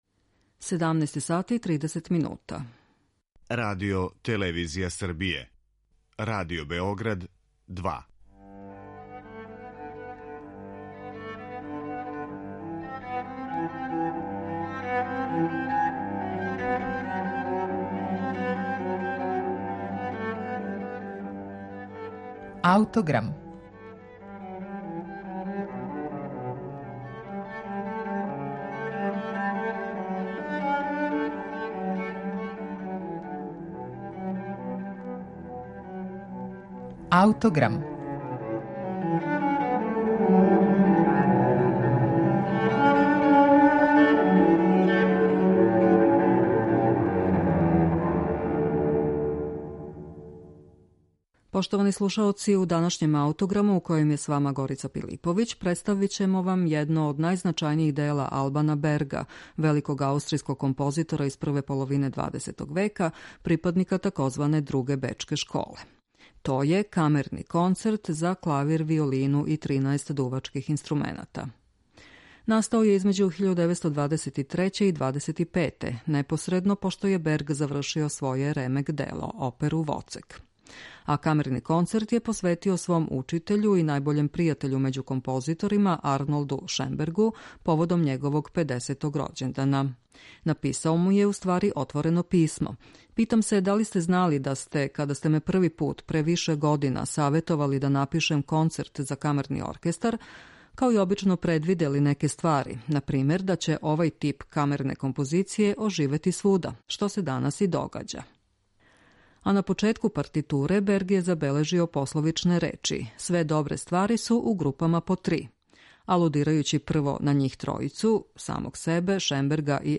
То је Камерни концерт за клавир, виолину и 13 дувачких инструмената.